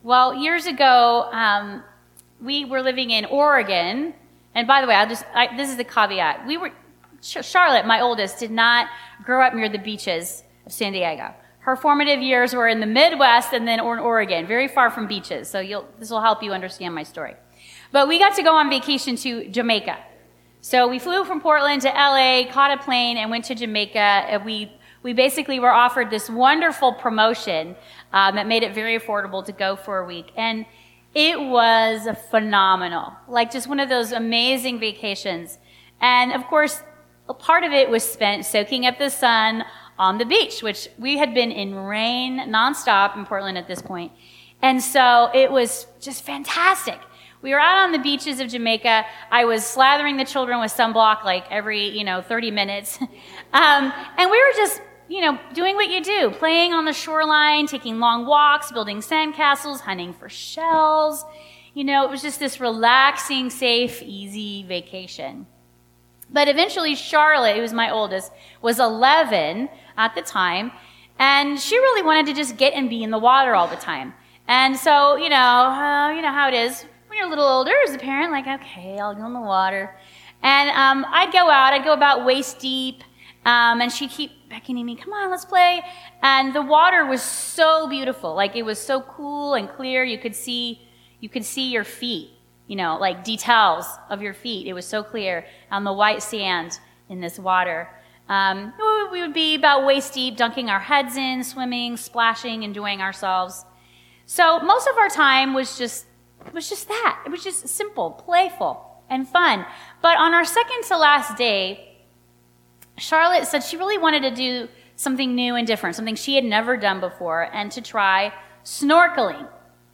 Sunday's Sermon